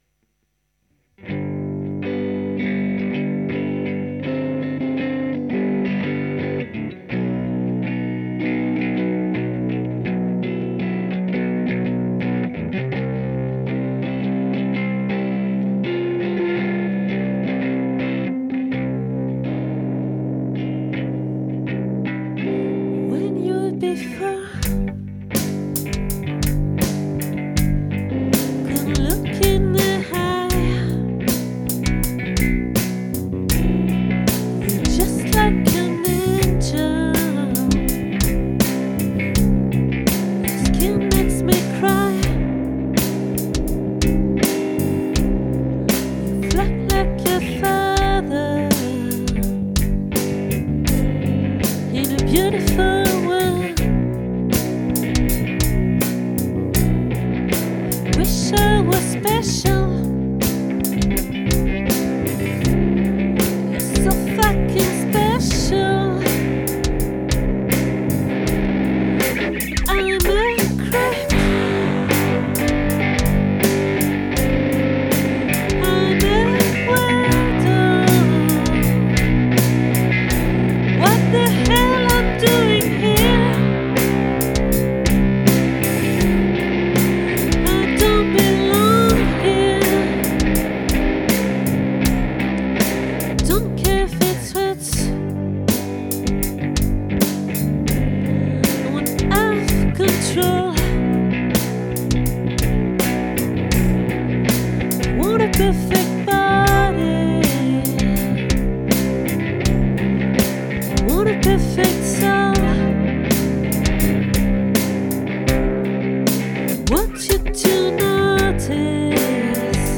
🏠 Accueil Repetitions Records_2024_12_09